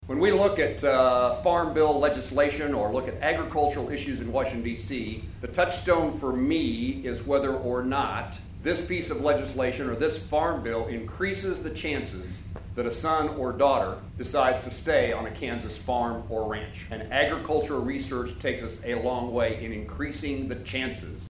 A groundbreaking ceremony for a new Agronomy Research and Innovation Center at Kansas State University had to be moved indoors Monday, due to rain, leading to a standing-room only crowd of guests inside the university’s Agronomy Education Center.
U.S. Senator Jerry Moran (R-Kan.) also spoke at Monday’s ceremony, noting he believes the work that will be accomplished, between private sector companies and agricultural researchers, will help spur workforce development and economic prosperity. He says it goes in line with considerations he takes into account when working on legislation, such as the farm bill.